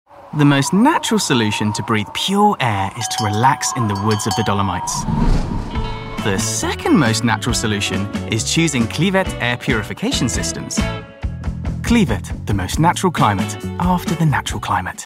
Inglés (Británico)
Comercial, Joven, Natural, Travieso, Amable
Comercial